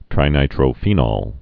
(trī-nītrō-fēnōl, -nôl, -nŏl)